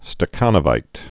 (stə-känə-vīt)